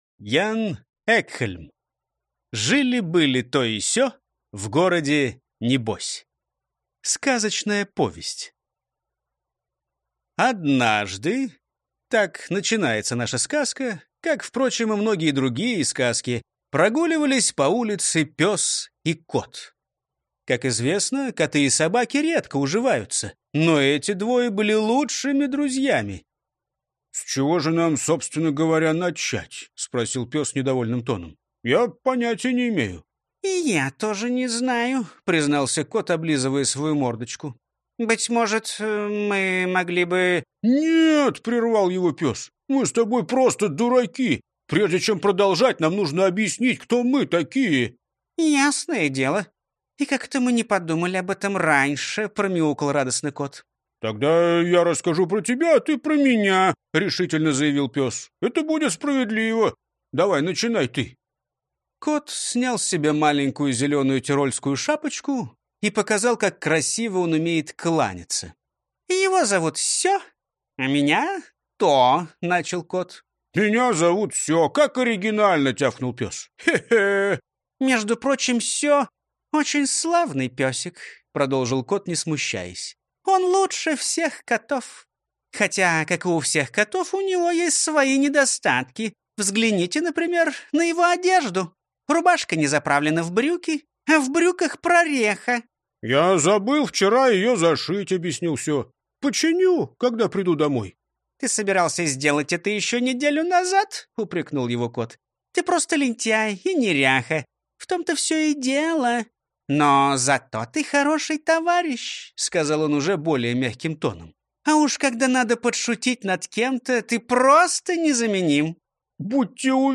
Аудиокнига Жили-были То и Сё в городе Небось | Библиотека аудиокниг